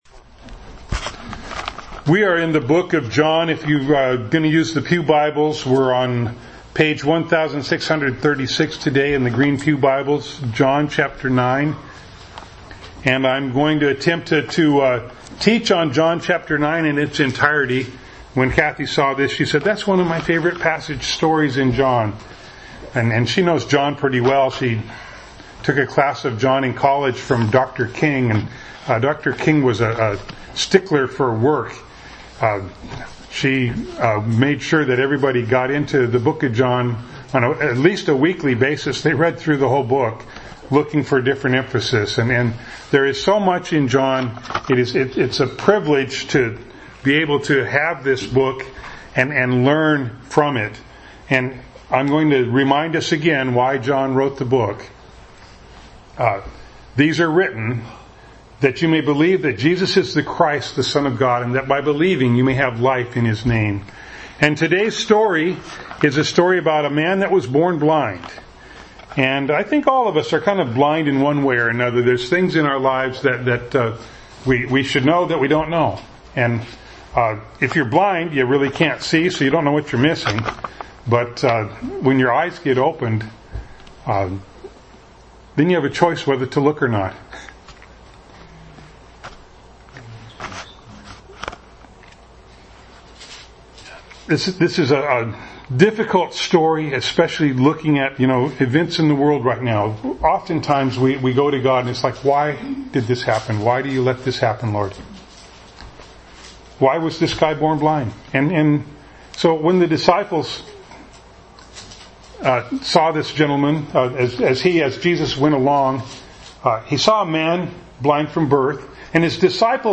John 9:1-41 Service Type: Sunday Morning Bible Text